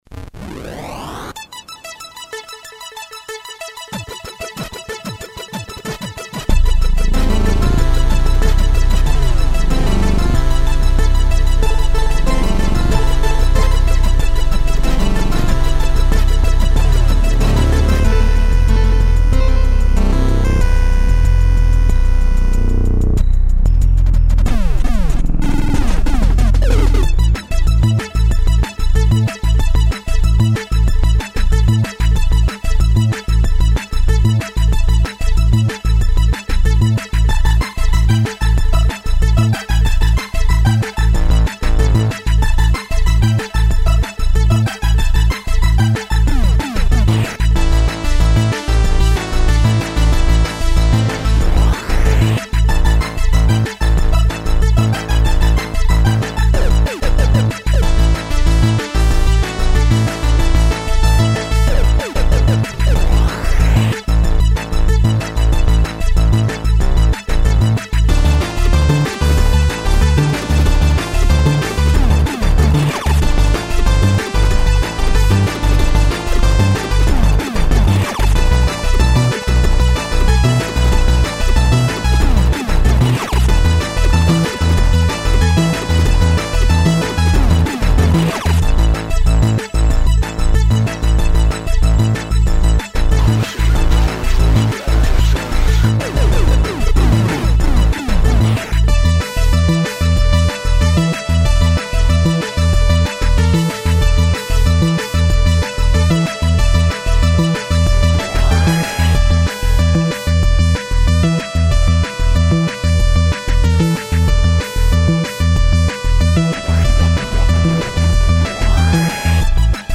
Waldorf Pulse + Oberheim Matrix 1000